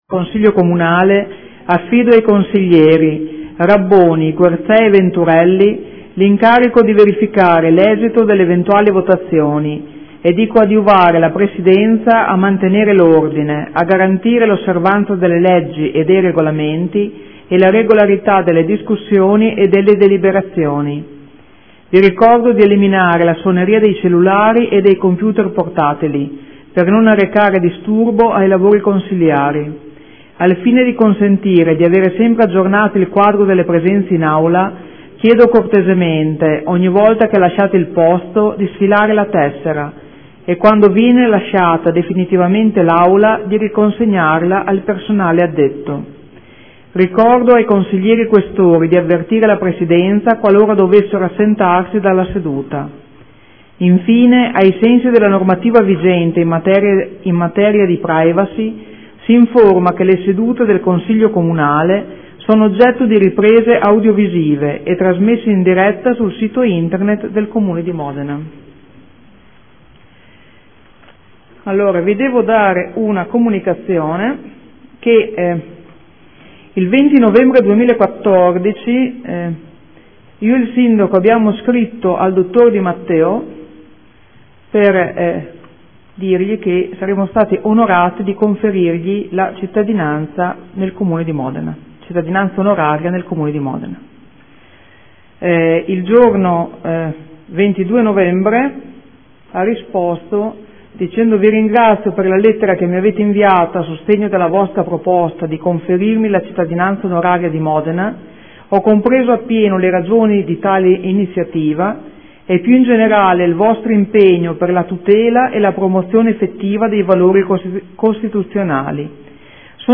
Presidente — Sito Audio Consiglio Comunale
Seduta del 27/11/2014 - Apertura lavori del Consiglio Comunale. Comunicazione su Cittadinanza Onoraria al Dr. Di Matteo.